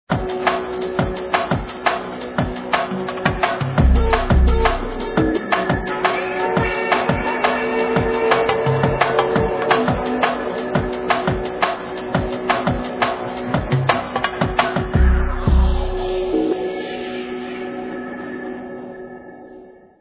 sledovat novinky v oddělení Dance/Drum & Bass